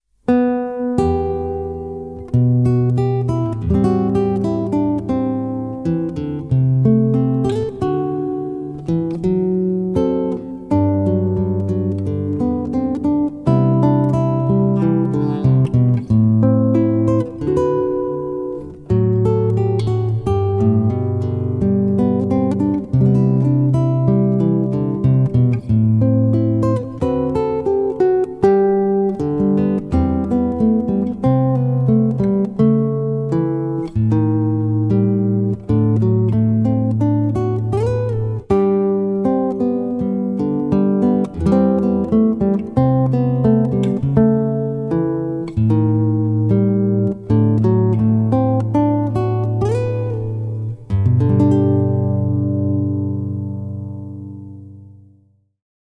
בעמוד זה תמצאו כ-80 עיבודי גיטרה ברמת ביניים